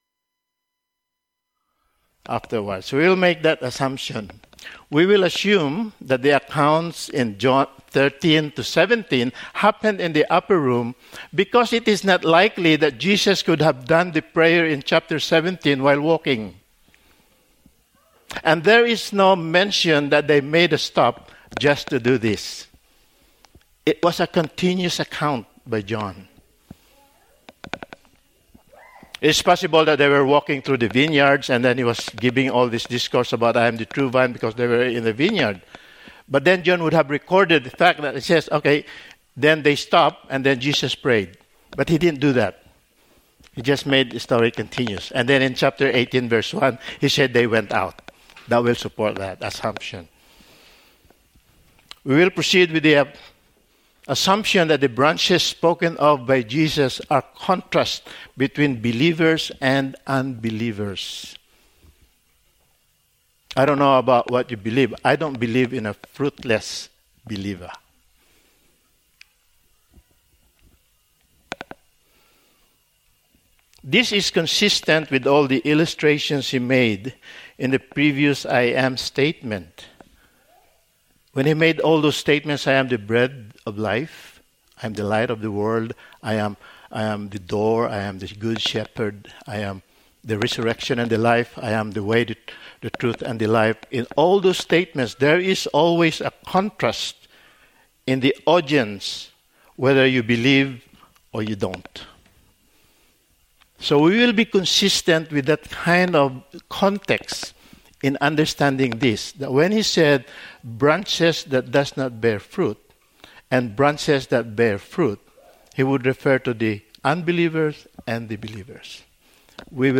Passage: John 15:1-11 Service Type: Sunday Morning